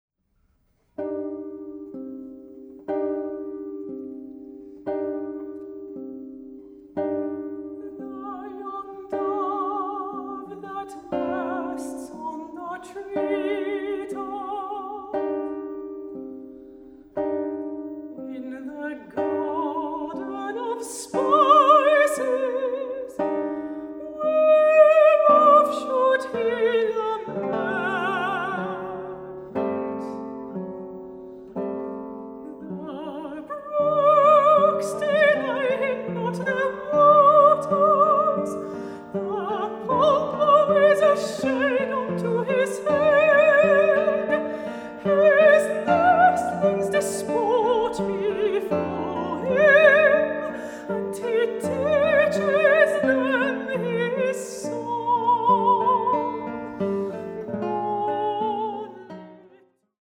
Guitar
Vocals